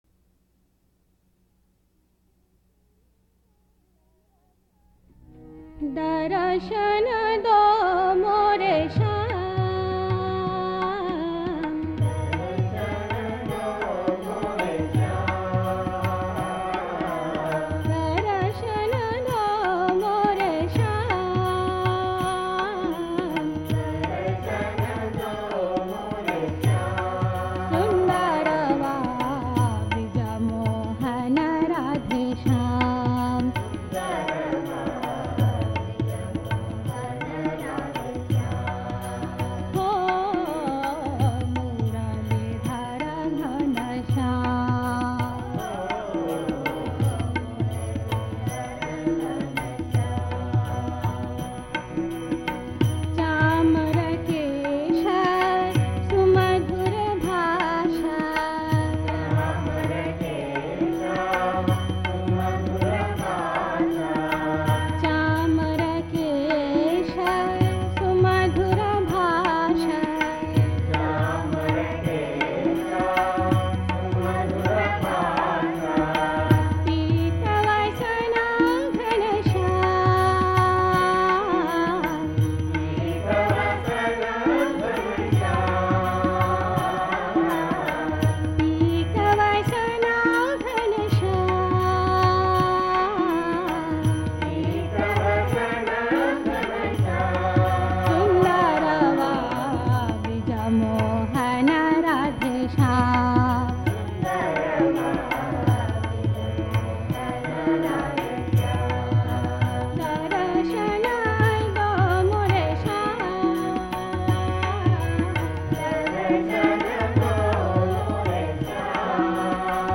1. Devotional Songs
~ Janasammohini
8 Beat / Keherwa / Adi
1 Pancham / C
5 Pancham / G
Lowest Note: n1 / B♭ (lower octave)
Highest Note: G2 / E (higher octave)